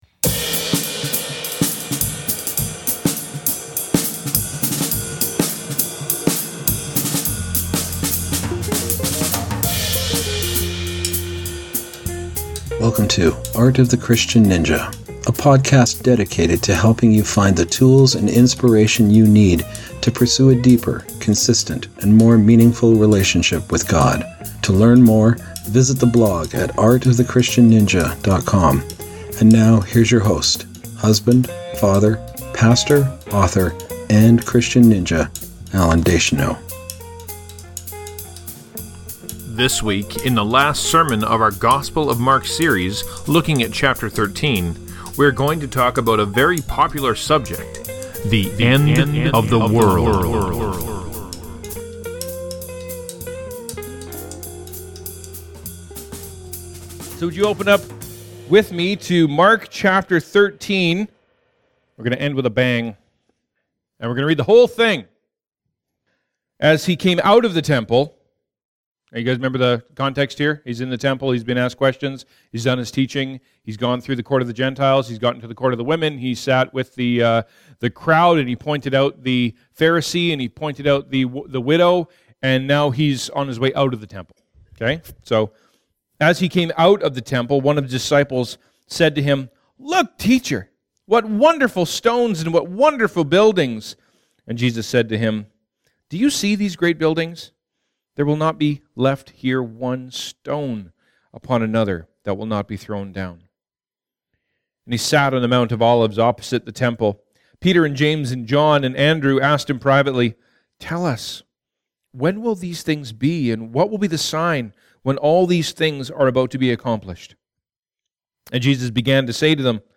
This is our last sermon in the Gospel of Mark and we’re going to end with a bang, covering the entirety of chapter 13.